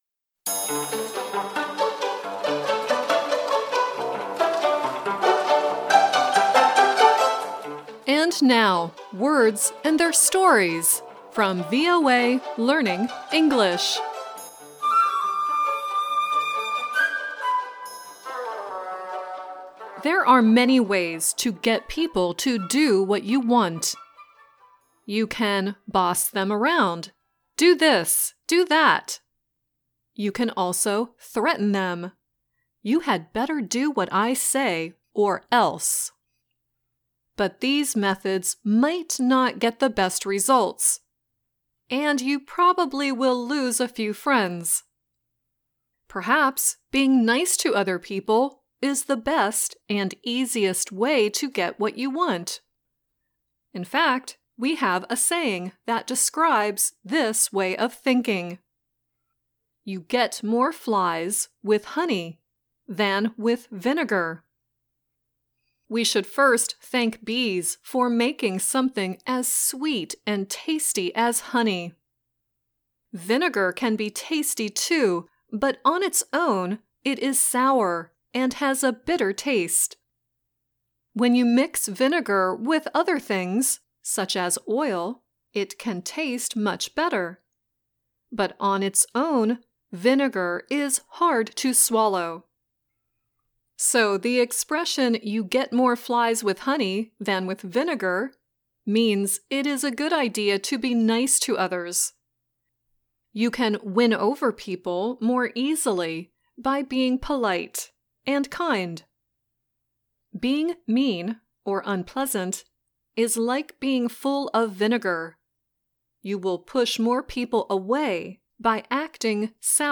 Kalau bisa diusahakan bacanya mirip dengan native speaker dibawah ini, oke..